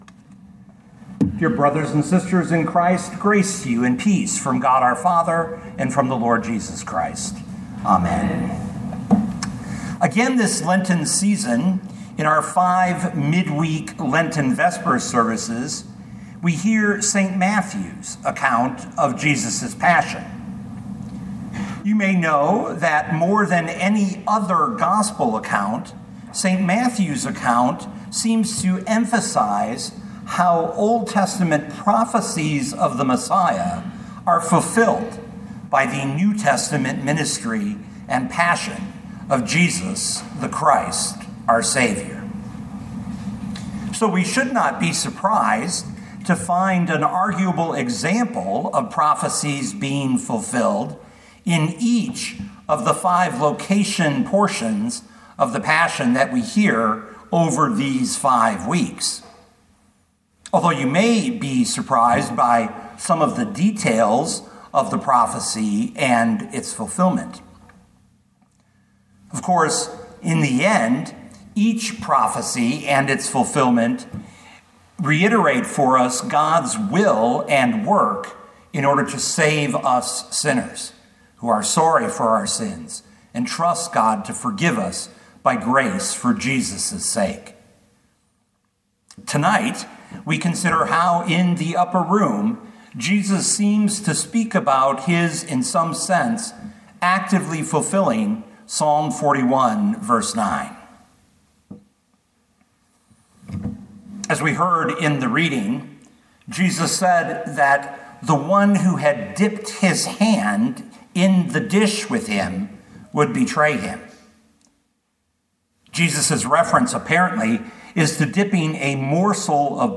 Matthew 26:23-24 Listen to the sermon with the player below, or, download the audio.